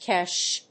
ケシュ